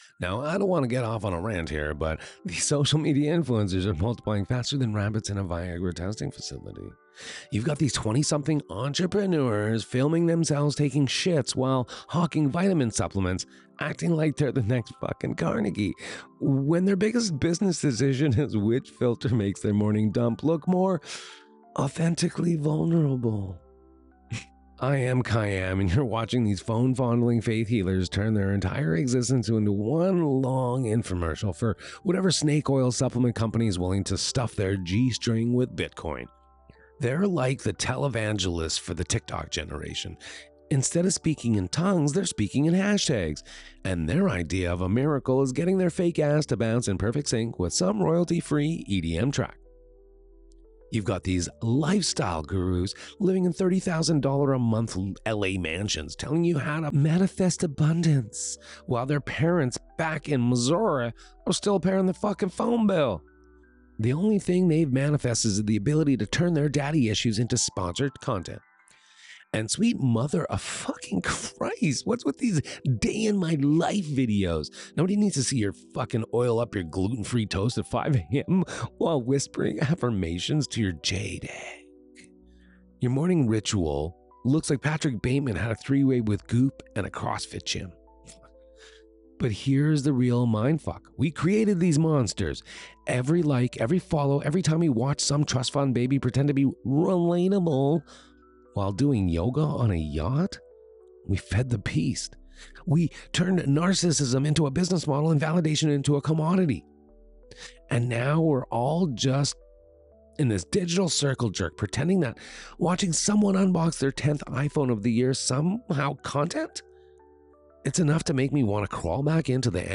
A volcanic eruption against the digital narcissistic feedback helix of social media influencers—those phone-fondling faith healers turning their existence into infomercials while manifesting nothing but the ability to monetize daddy issues.
015-RANT.mp3